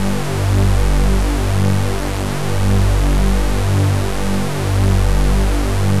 G1_jx_phat_lead_1.wav